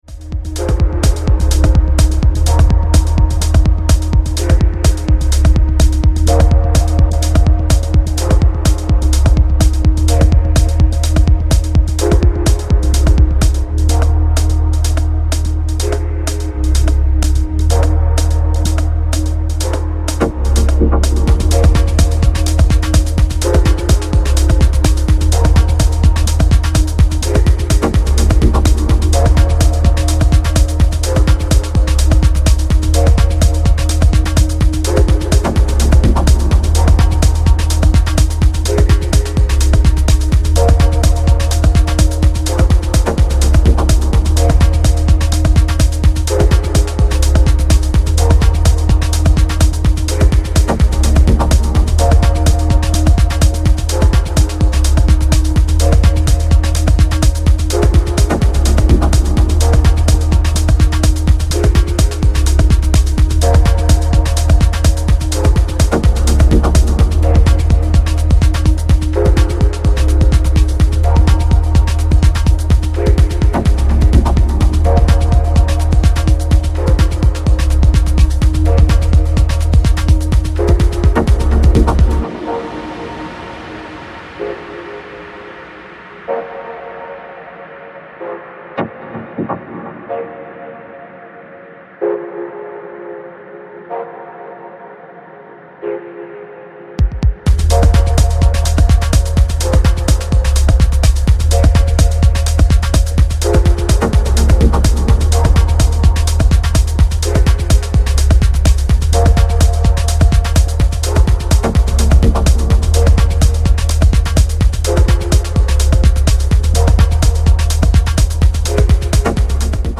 Straight forward techno tracks with nice athmosphere.